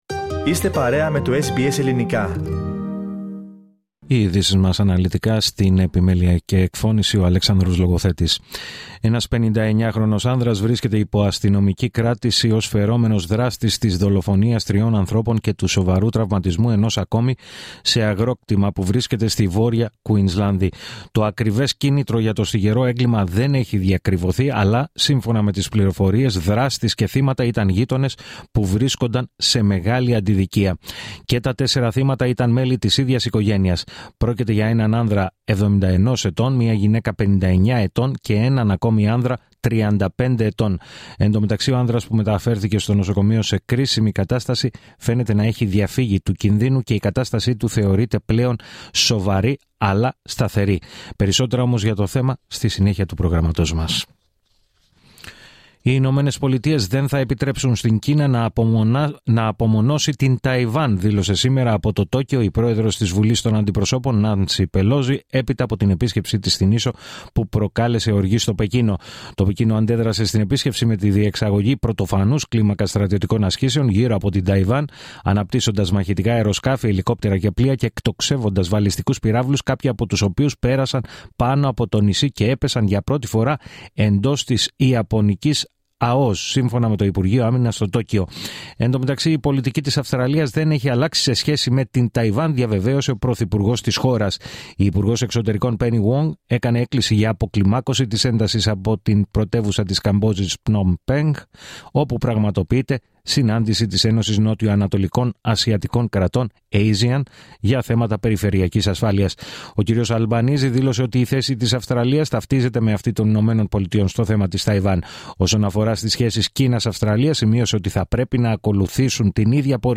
Το αναλυτικό δελτίο ειδήσεων του Ελληνικού προγράμματος της ραδιοφωνίας SBS, στις 16:00.